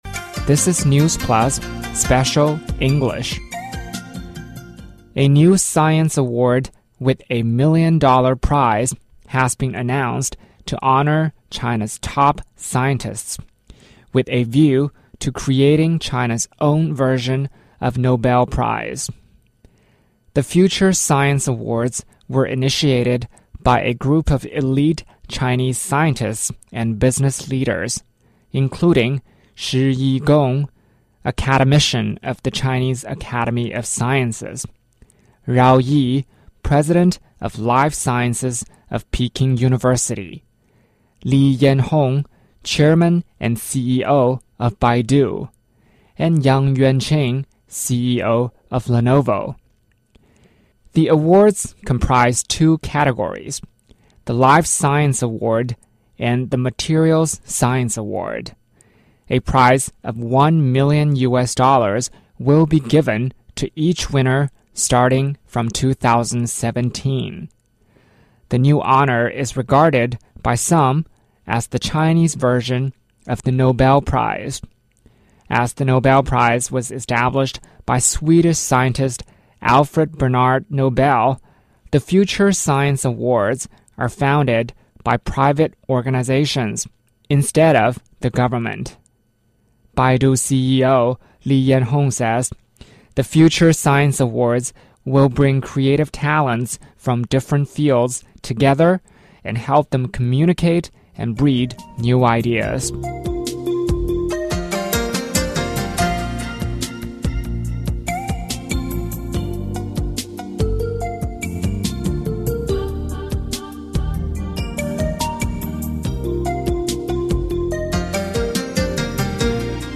News Plus慢速英语:中国民间创立未来科学大奖 天津滨海新区严控危化企业